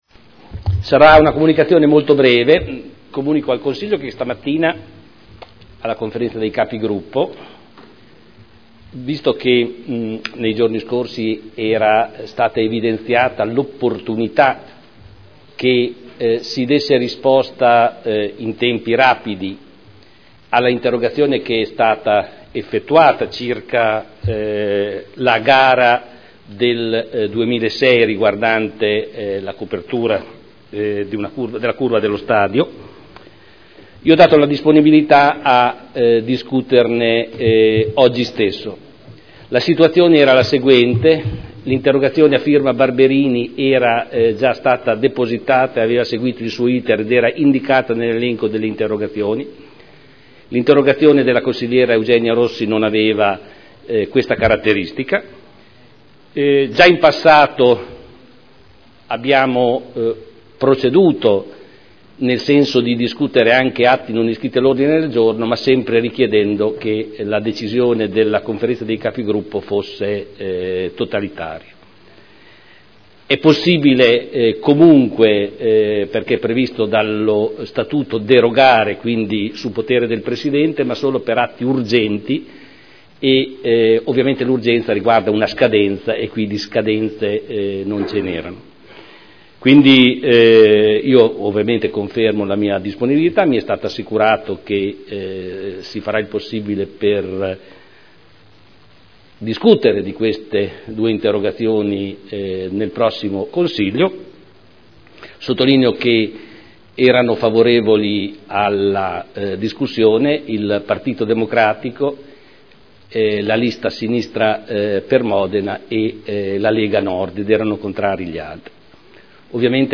Seduta del 07/03/2011. Comunicazione del Sindaco Pighi